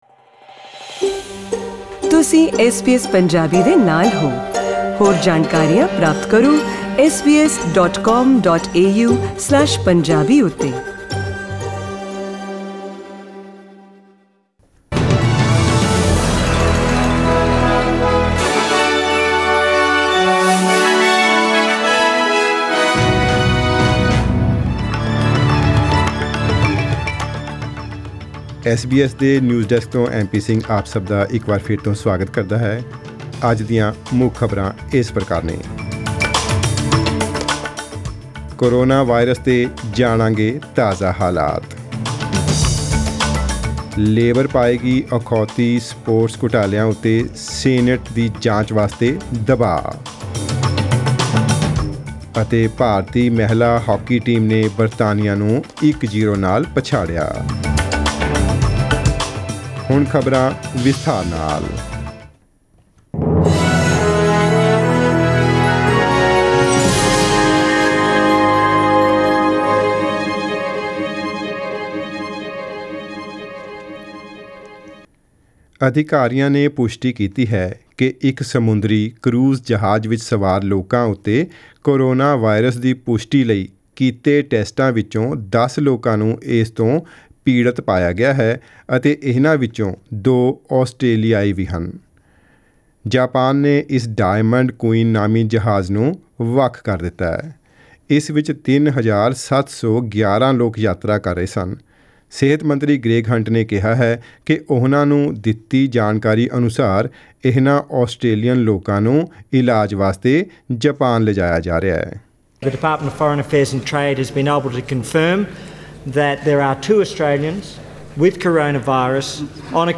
SBS Punjabi News: 5 February 2020